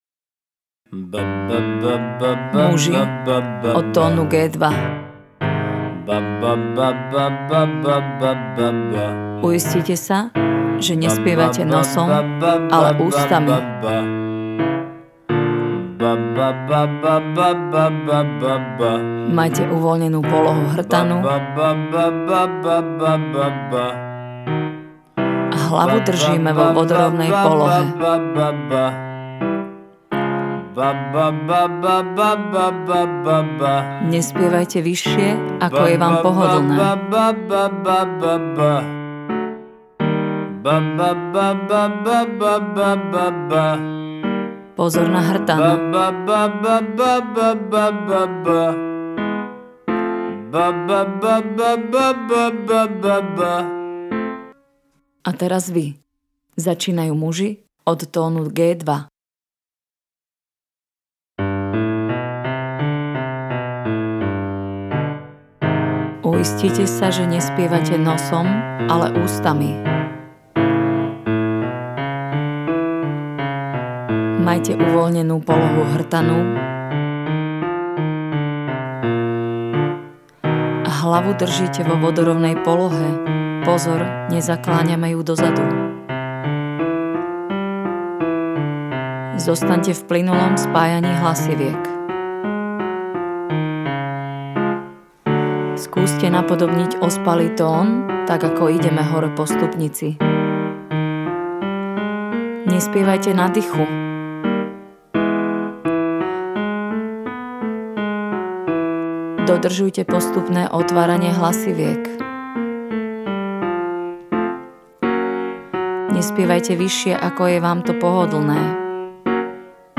Cvičenie na hrudný hlas Ba Ba-Muži.
Cvicenie-Ba-Ba-.Muzi_.mp3